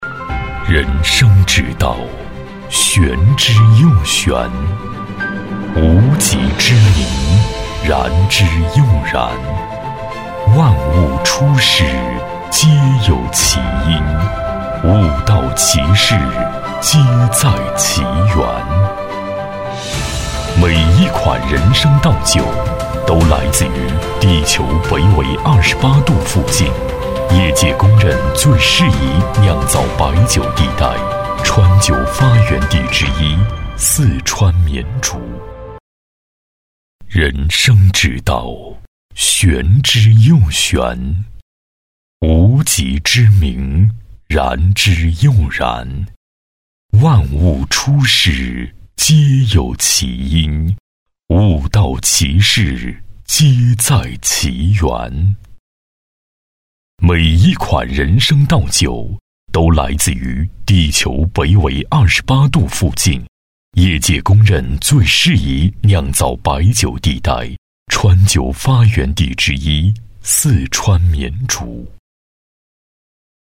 男国148_广告_酒类_酒类广告.mp3